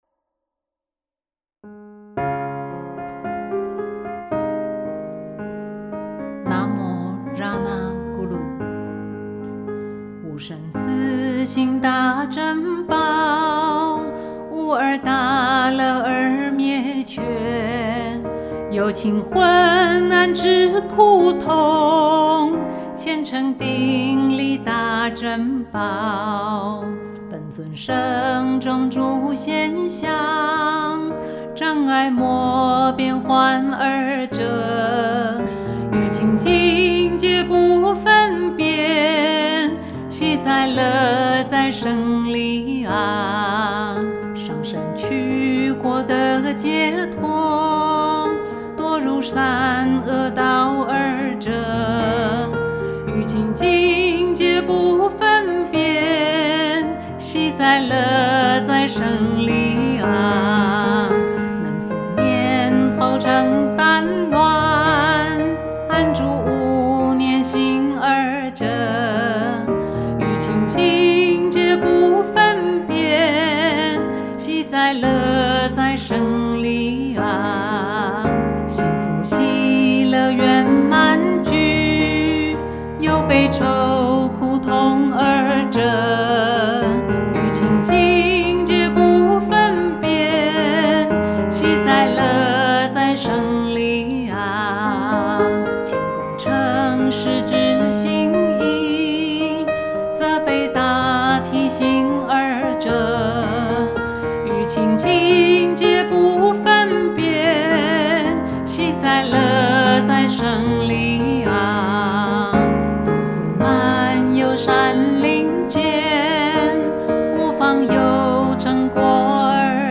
鋼琴配樂